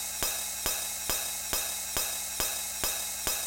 OP RIDE   -R.wav